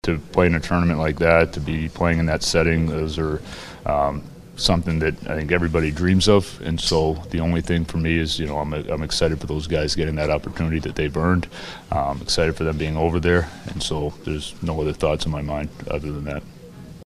Penguins coach Dan Muse was informed of the injury as he walked from the practice ice to meet the media.  He had no idea of how badly Crosby was hurt, but he said he can’t begrudge players wanting to play in the Olympics, so he has to accept the risk.